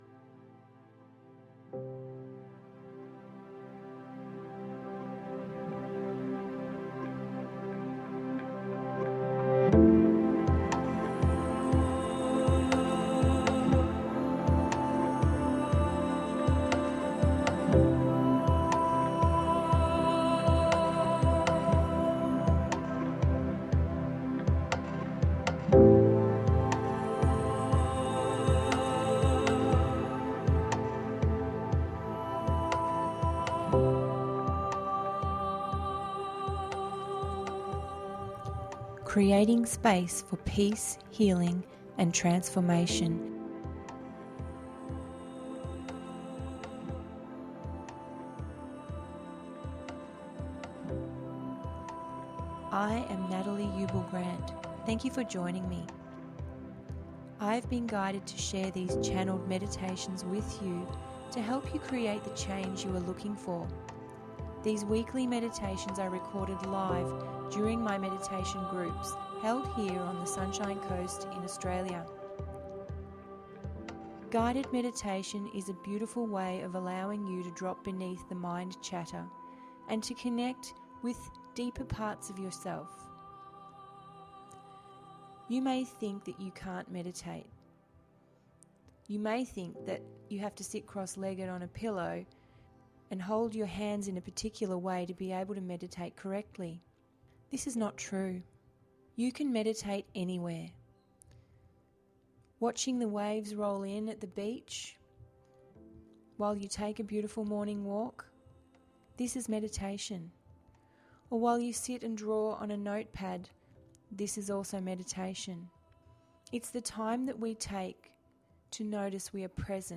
Guided Meditation duration approx. 19 mins Taking time to relax is more important than ever.